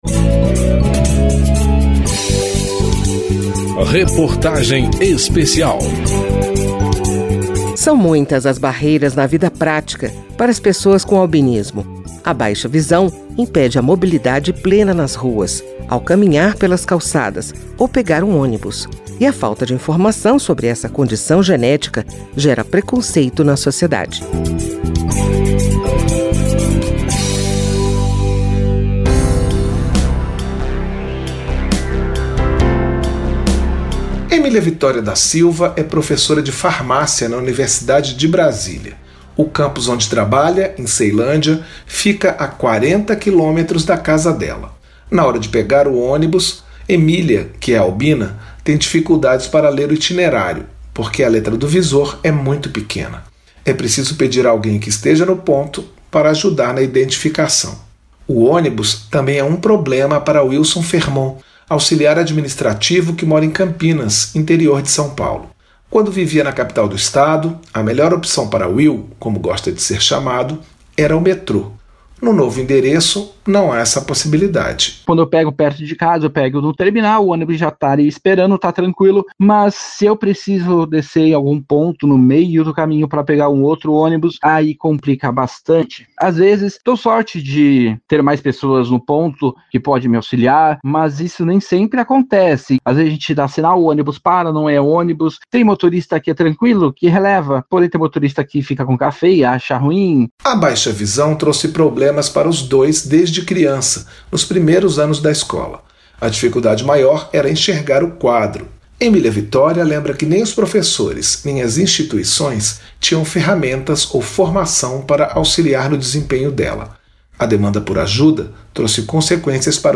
Reportagem Especial
Uma professora universitária de Brasília e um auxiliar administrativo de Campinas contam como as consequências do albinismo interferem na vida prática, desde as dificuldades para pegar um ônibus até o preconceito das outras pessoas por falta de informação.